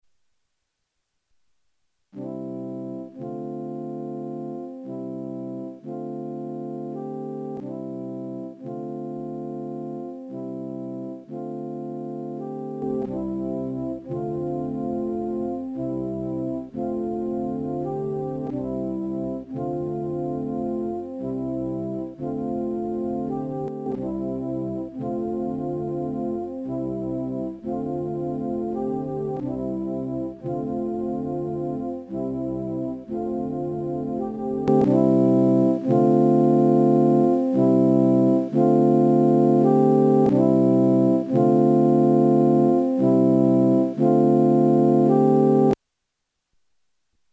just to illustrate what I’m saying, this is the audio test - I just looped something really quickly with a looper pedal, it’s not anything scientific.
1. footswitch engaged (effect off)
2. footswitch engaged (effect 1)
3. footswitch engaged (effect 2)
4.true bypass (footswitch disengaged)
I’m sure this will help, I did no processing of the audio, I recorded it straight into a zoom recorder. I leveled it for the true bypass so it wouldn’t clip when I disengaged the the footswitch/effect. it’s a super old non-midi yamaha fm keyboard so the audio is not clean but it was convenient at the time so that’s what I used.